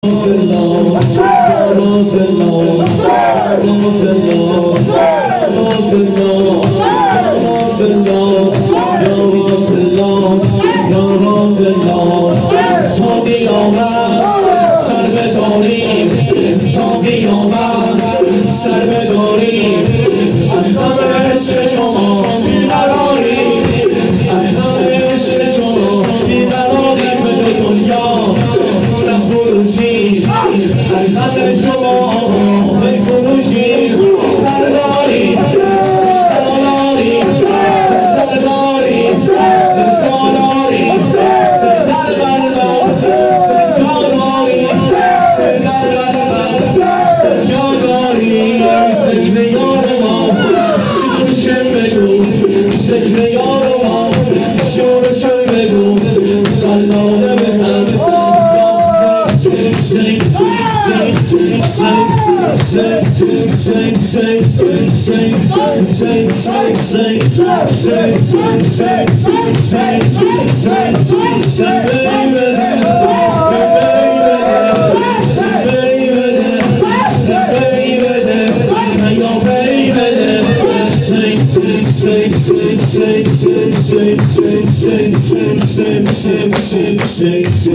مراسم هفتگی۹۳/۱۱/۱۵
شور